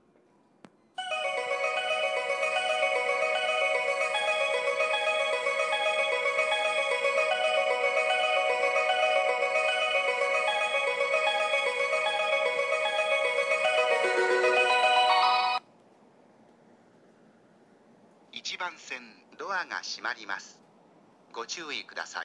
スピーカーは小ボスで音質がたいへんいいです。
発車メロディー余韻切りです。